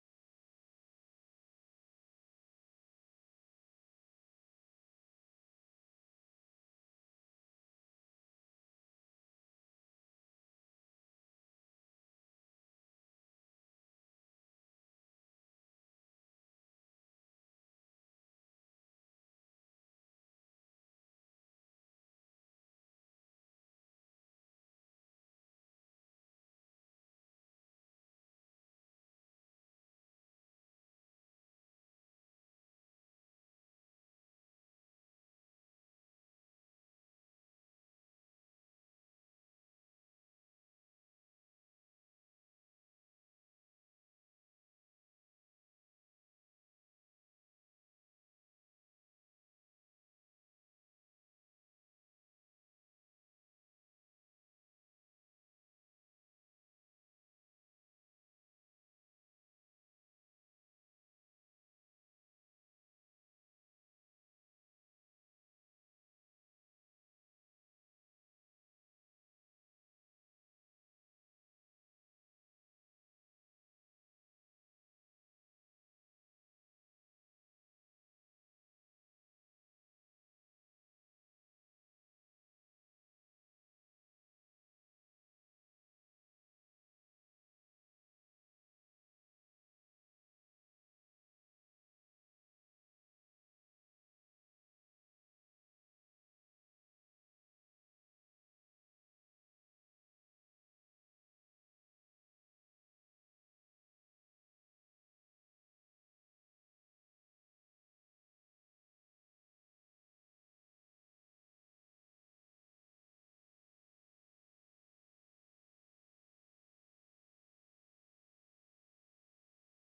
God’s Armor Sermon August 24
Being-Strong-in-the-Lord-–-Gods-Armor-Sermon-Audio-CD.mp3